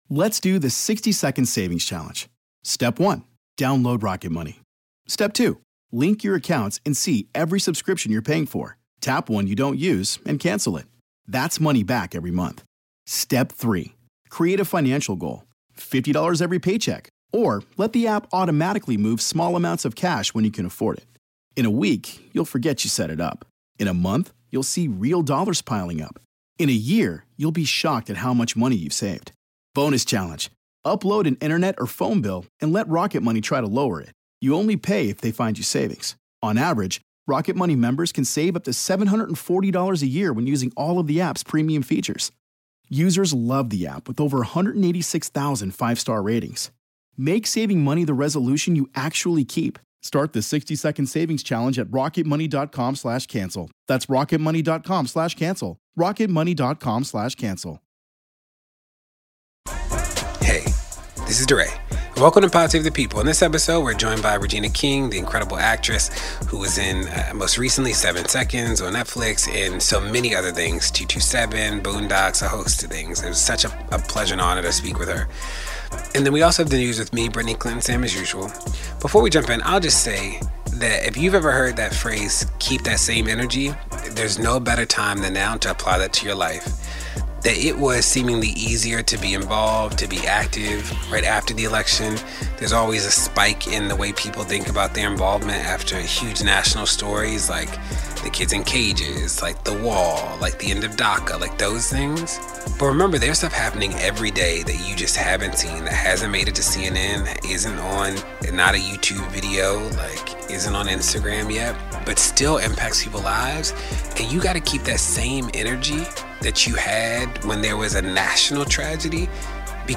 Actress Regina King joins to discuss her most recent project Seven Seconds, a series on Netflix that focuses on police violence.